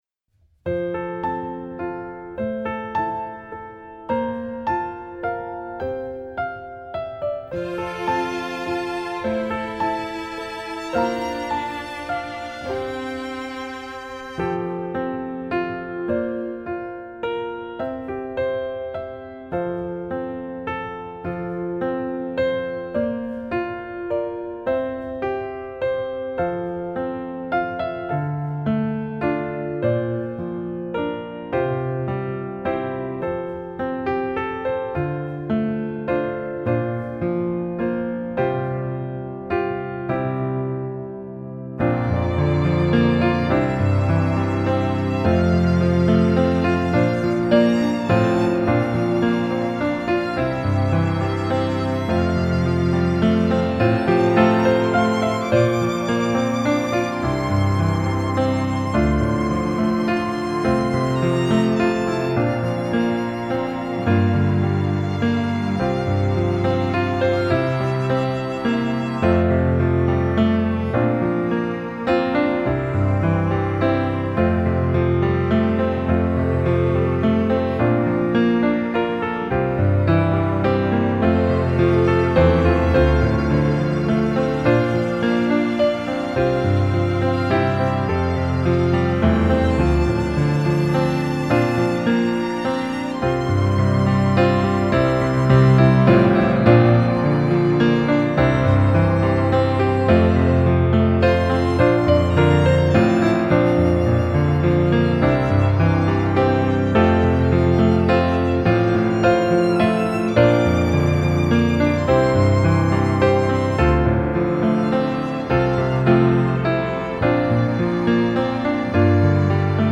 original recording